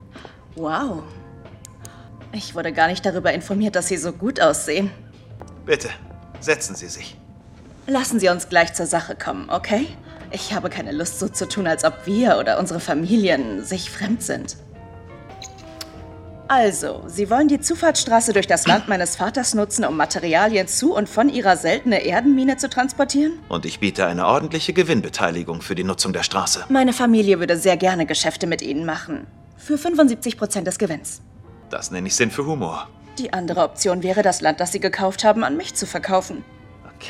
hell, fein, zart, sehr variabel, markant
Jung (18-30)
Lip-Sync (Synchron)